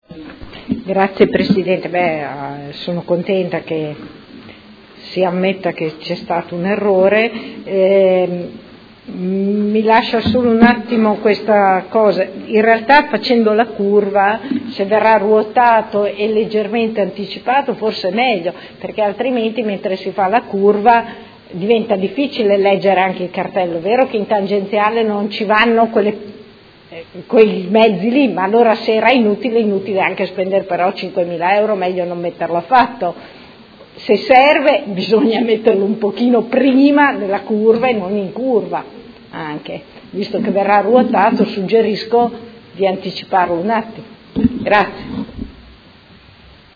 Seduta del 29/09/2016 Replica a risposta Assessore. Interrogazione della Consigliera Santoro (IDEA - Popolarti Liberali) avente per oggetto: Assurdo posizionamento di tabellone prescrittivo all’ingresso in tangenziale: chiarimenti